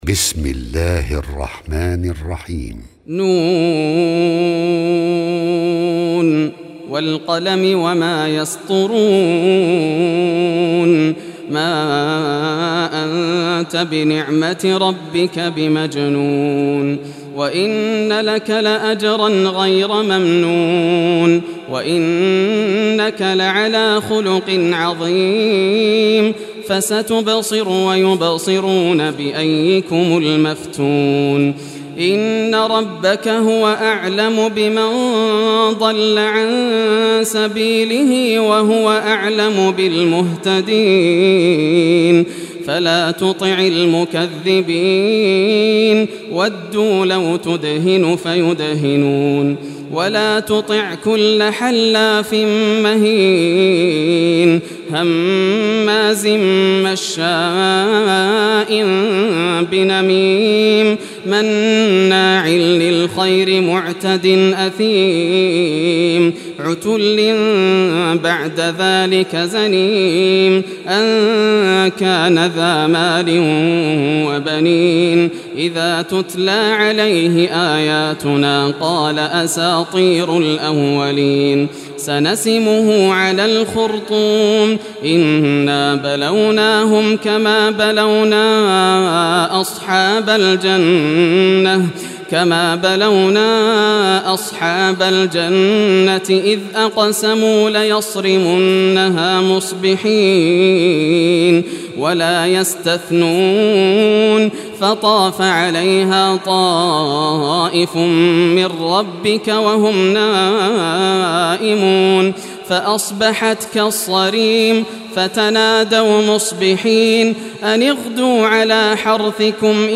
Surah Al-Qalam Recitation by Yasser al Dosari
Surah Al-Qalam, listen or play online mp3 tilawat / recitation in Arabic in the beautiful voice of Sheikh Yasser al Dosari.